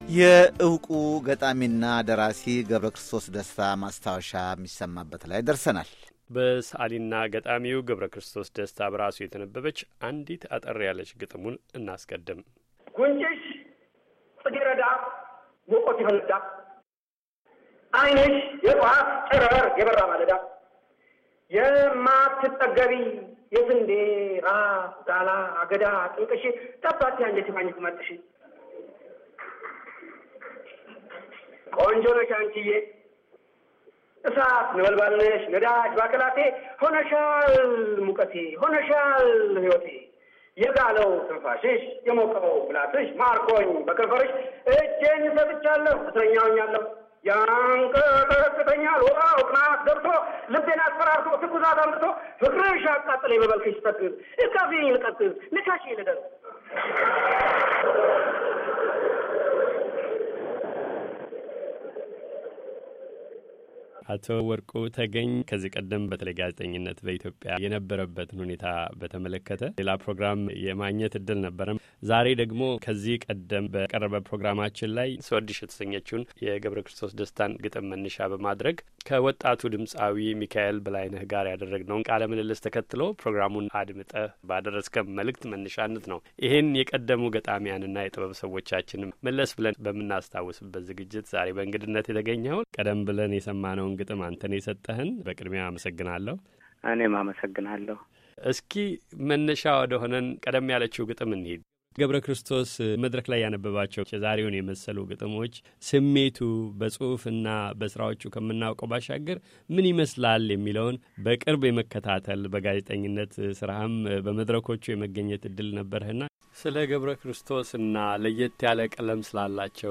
ሥመ ጥሩ ሠዓሊና ገጣሚ ገብረ ክርስቶስ ደሰታ ከሰላሳ ዓመታት በፊት በገዛ አንደበቱ ያነበባት አንዲት ግጥም መነሻነት የተቀናበረ ዝግጅት ነው። ወደ አዲስ አበባ ዩኒቨርሲቲው የግጥም ጉባኤ ትወስደናለች።